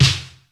E032SNARE3.wav